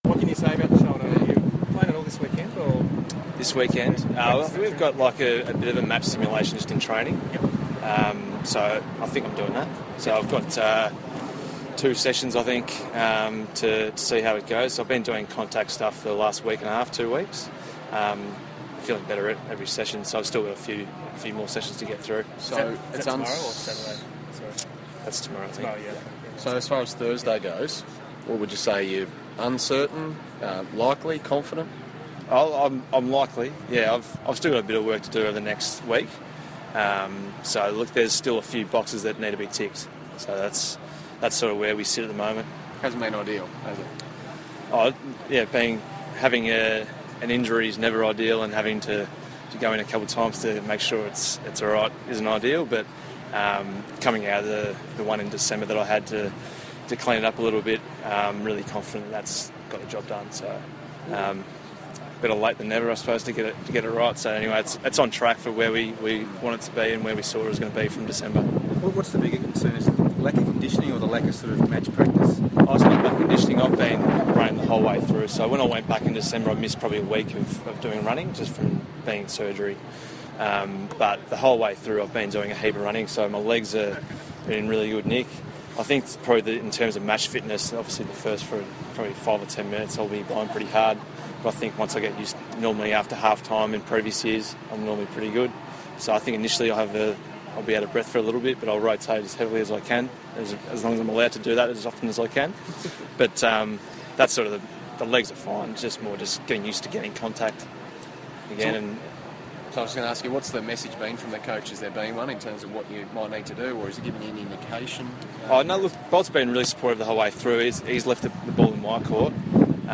Marc Murphy press conference - AFL Season Launch
Carlton captain Marc Murphy has a wide-ranging interview with the media at the 2016 AFL Season Launch.